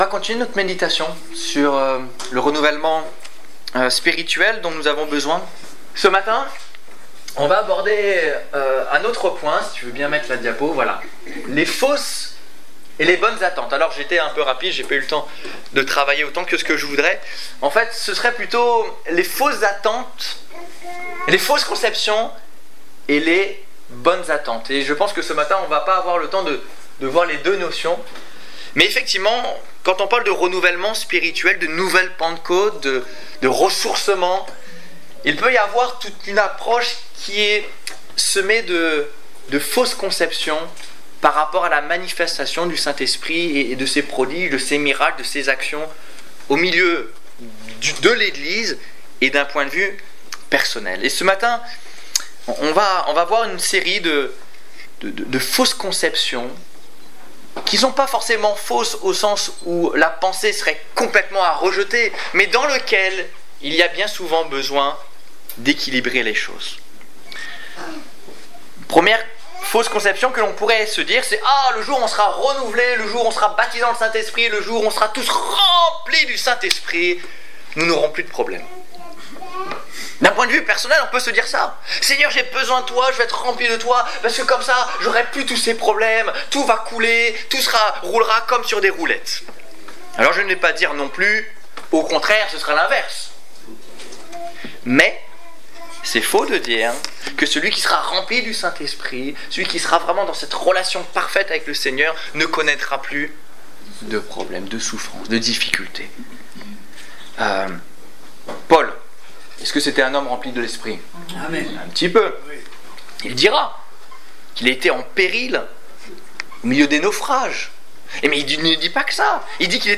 Culte du 7 décembre 2014 Ecoutez l'enregistrement de ce message à l'aide du lecteur